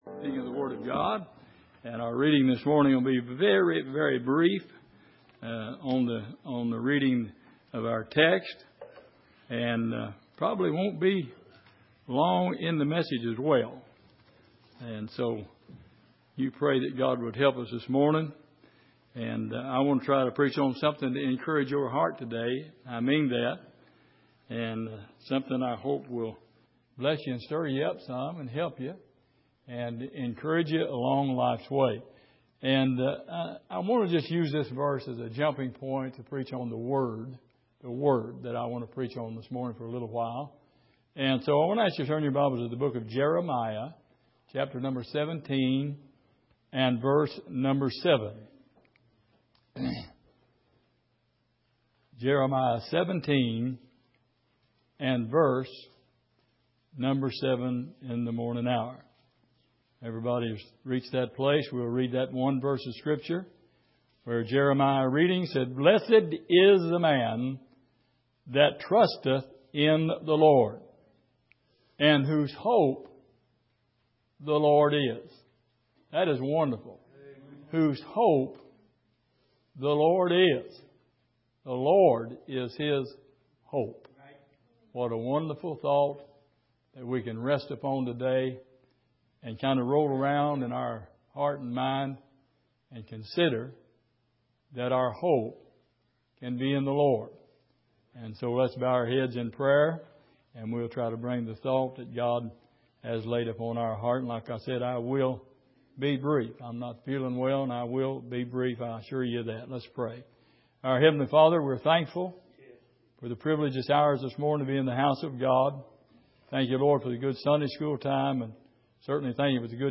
Jeremiah 17:7 Service: Sunday Morning The Great Virtue Of Hope « Who Caused It All?